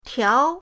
tiao2.mp3